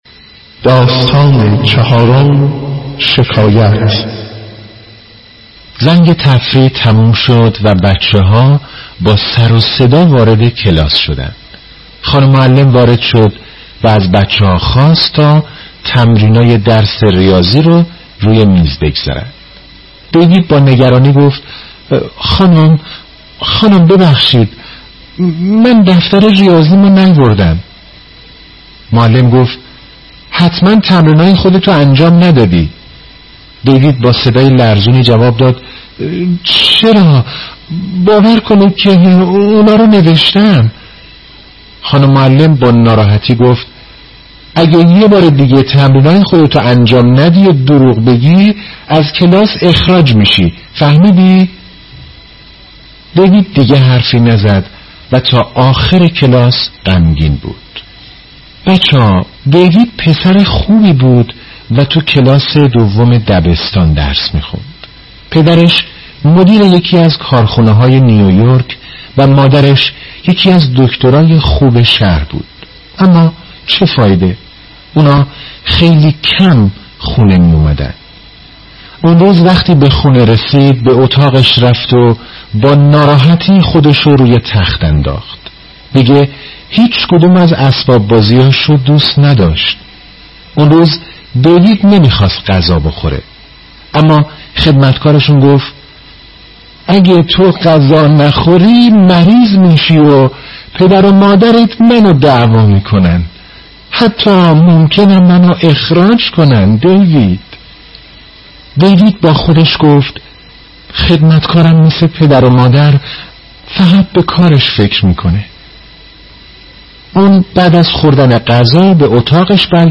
خوانش ده دقیقه ای کتاب نخل و نارنج